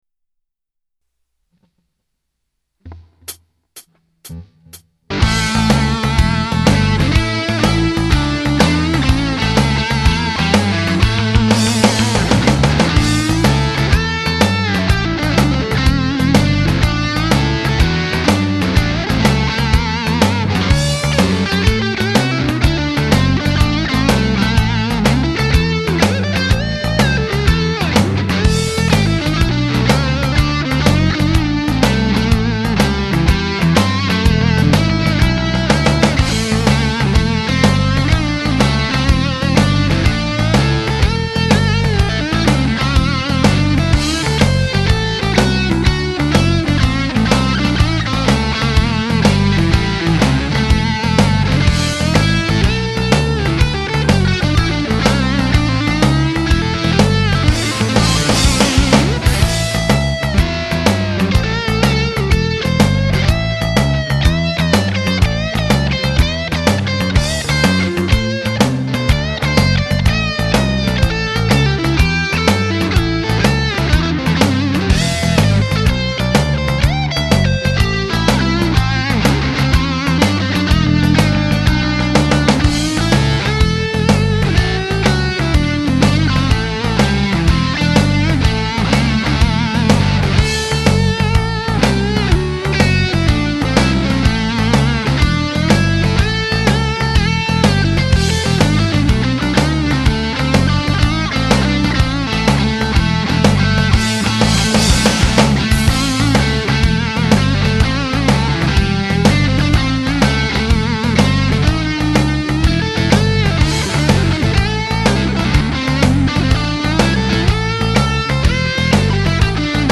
Beim Saitenziehen bin ich manchmal etwas Sharp, aber irgendwie habe ich Spaß an diesem Teil :-)